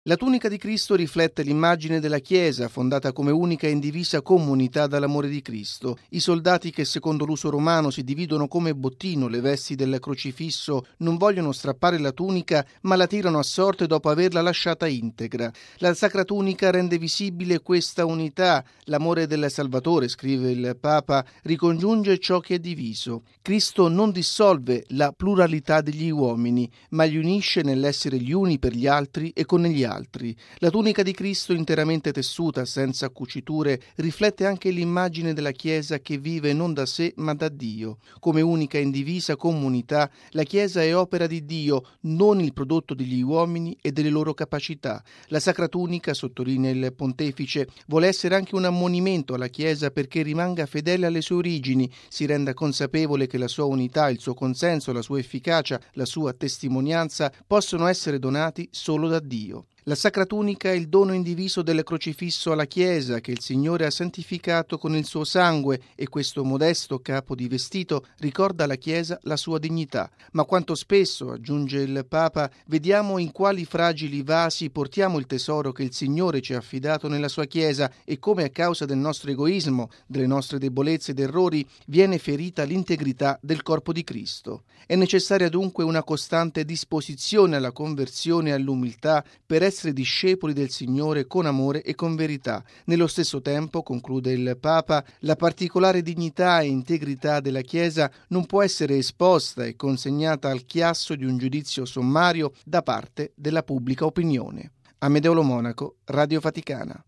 E’ quanto scrive Benedetto XVI in un Messaggio rivolto al vescovo di Treviri, mons. Stephan Ackermann, in occasione dell’apertura ieri, nel Duomo della città tedesca, del Pellegrinaggio alla Sacra Tunica, reliquia che ci riporta ai “drammatici momenti della vita terrena di Gesù”, alla sua morte in Croce. Il servizio